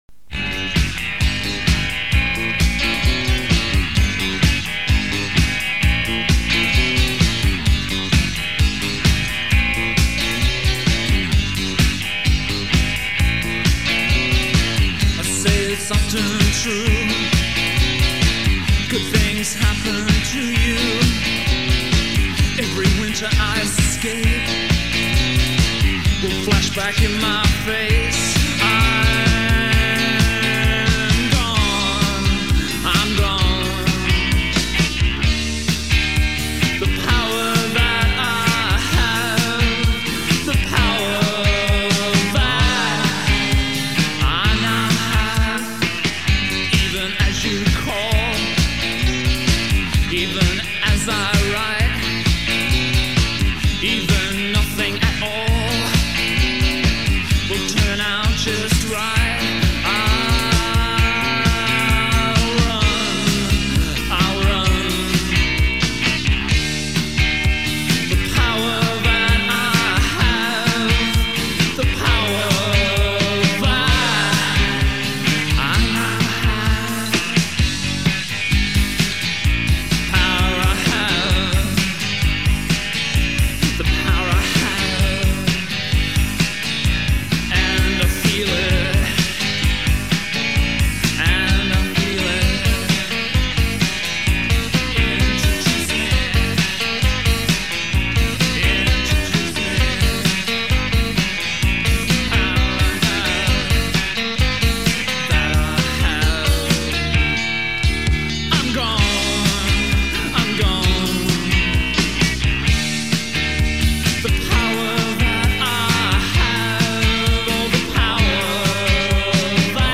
some catchy tunes